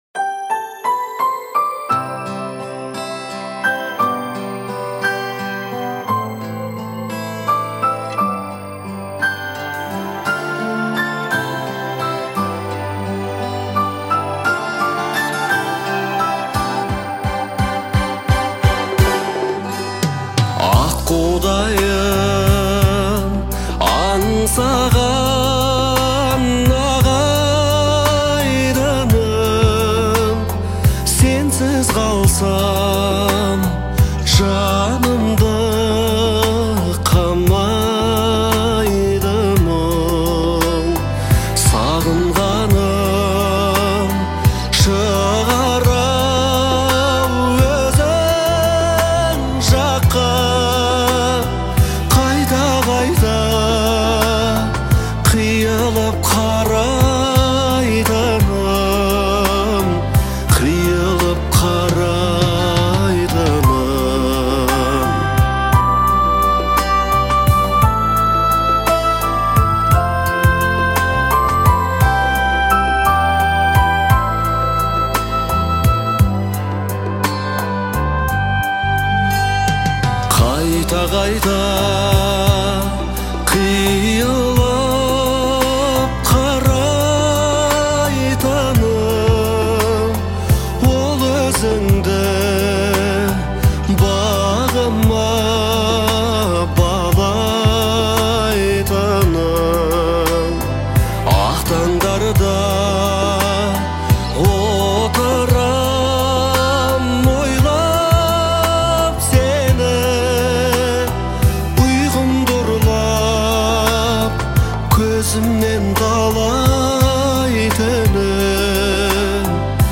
Звучание трека характеризуется мелодичностью и душевностью